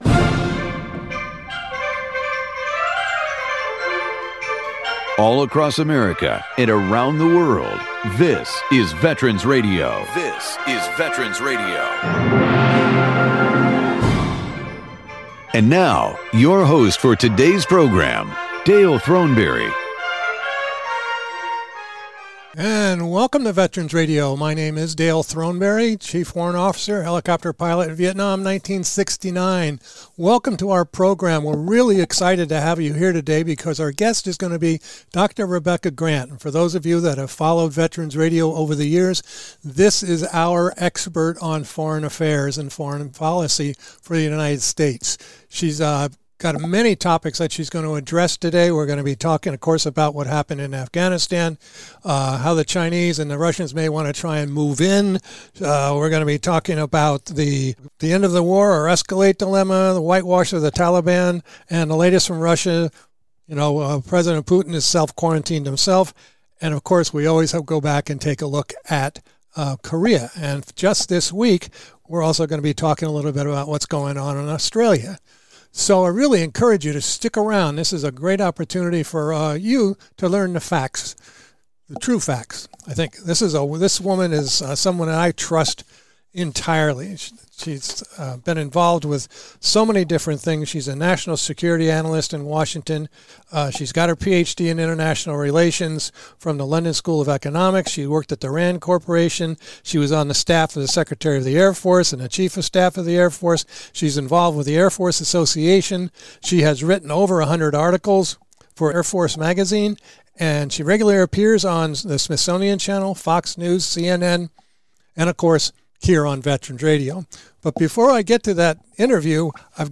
one hour radio broadcast